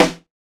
BR Snare hard.WAV